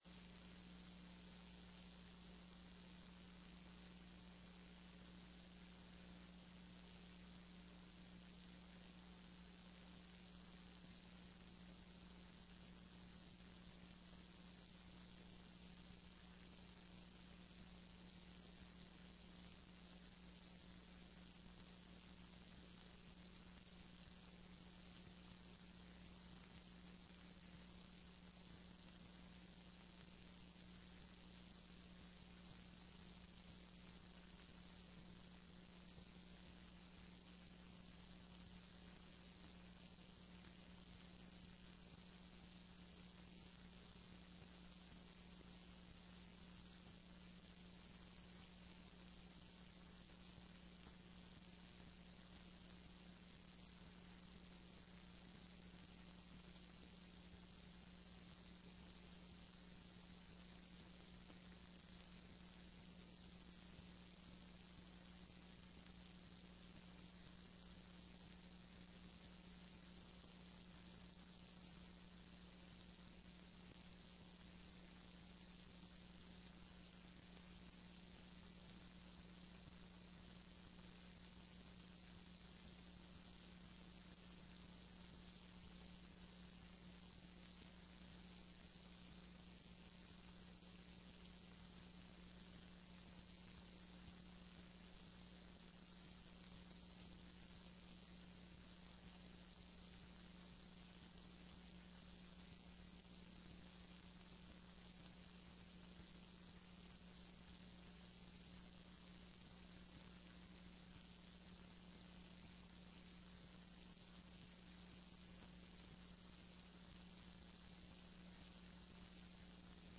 Sessão Plenária TRE-ES dia 09/02/15